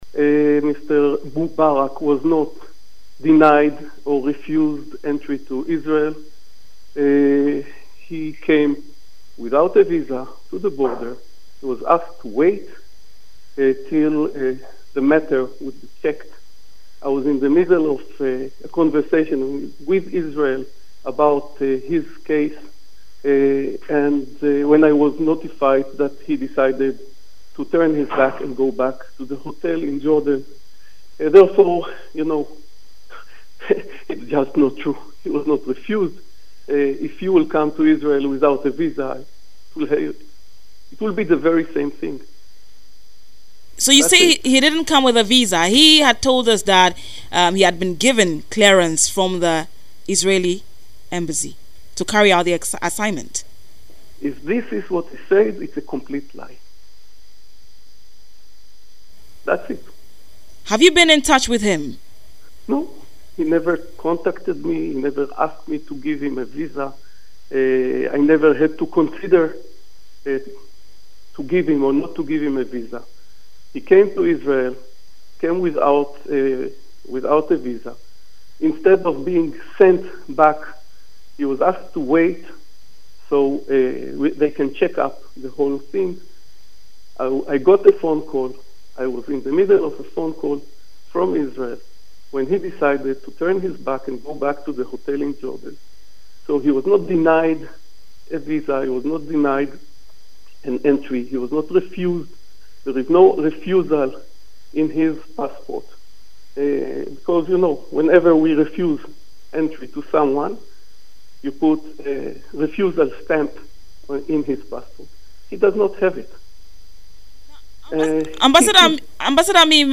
The Israeli ambassador to Ghana Ami Mehl lost his cool on live radio on Wednesday as he spewed swear words in the wake of alleged refusal of Visa to a Ghana MP to enter Palestine.